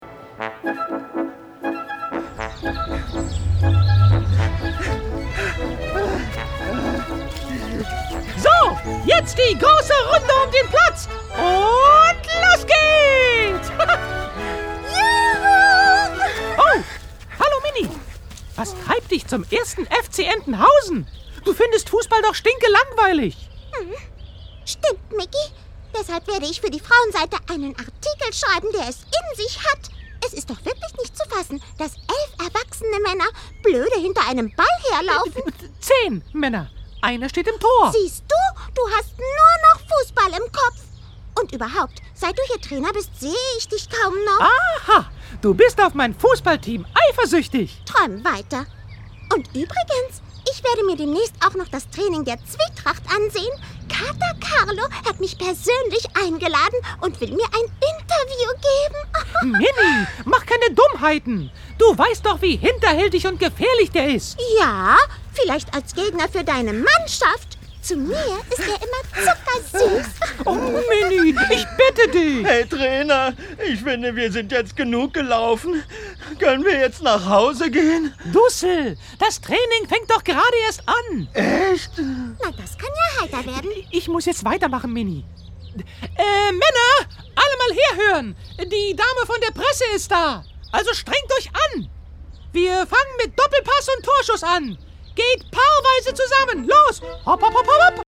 Ein Original-Hörspiel von Disney mit der wohl bekanntesten Figur aller Zeiten!
Hörspiel